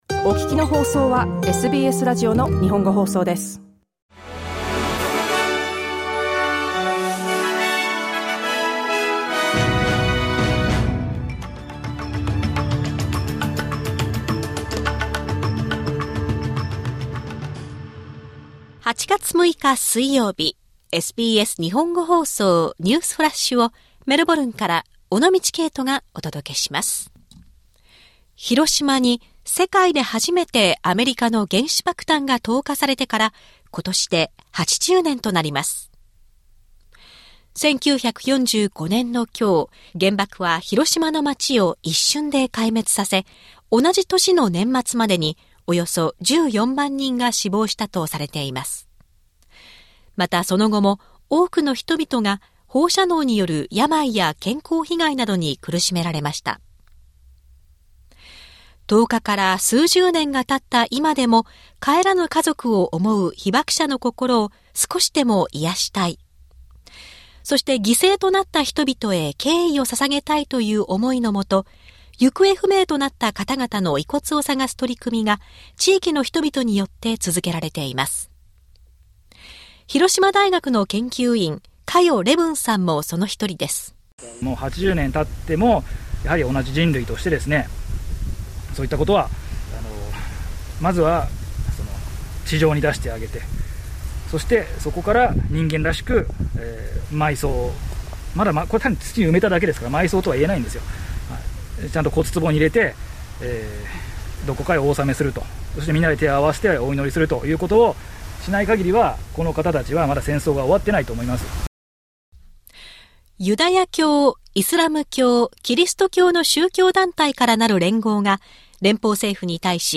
SBS日本語放送ニュースフラッシュ 8月6日 水曜日